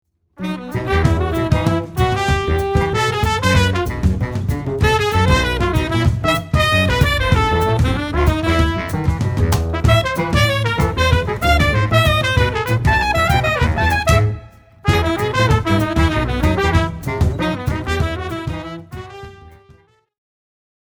recorded June 2005 at Fattoria Musica, Osnabrück
New Vocal Jazz